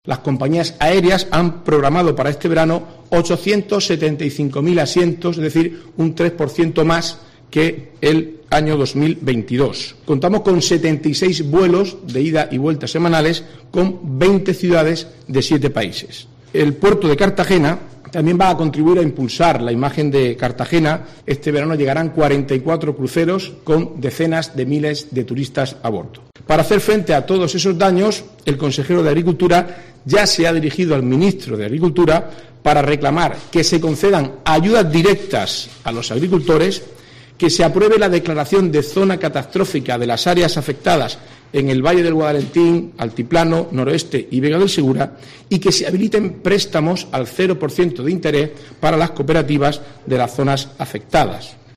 Marcos Ortuño, consejero de turismo en funciones